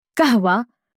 coffee-in-arabic.mp3